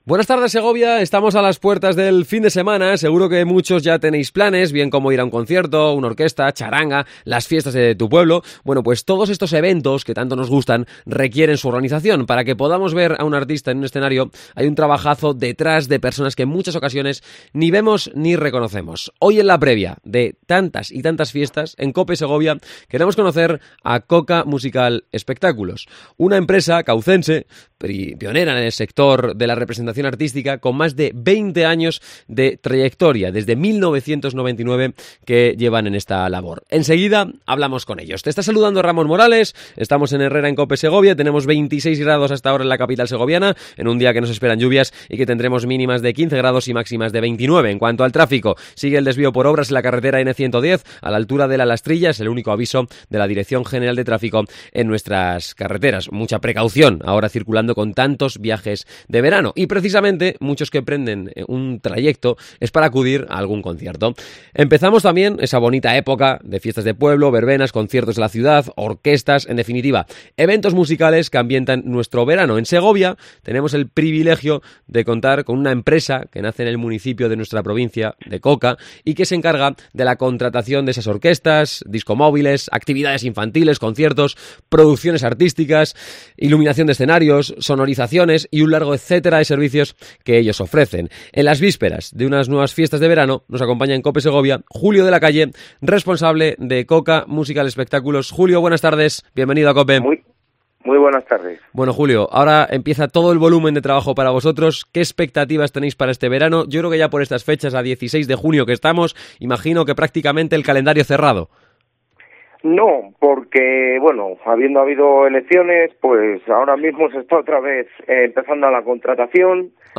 Entrevista Coca Musical Espectáculos